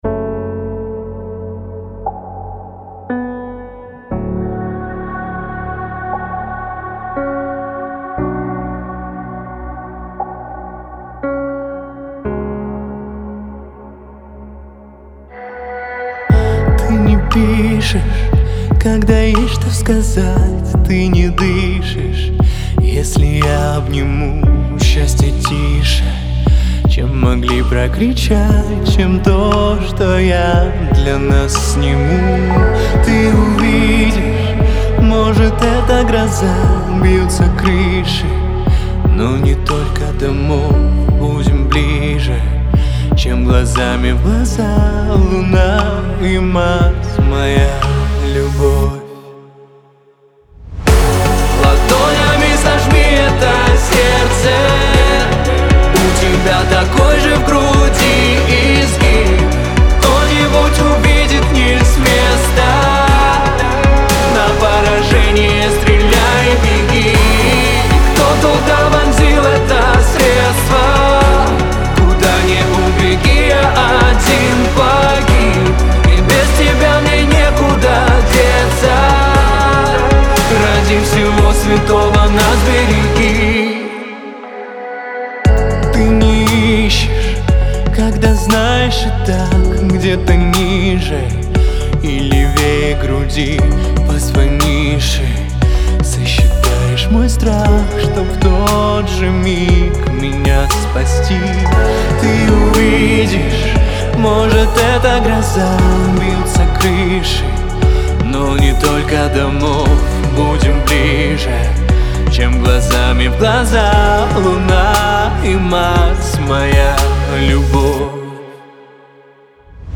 отличается мягкими мелодиями и душевным вокалом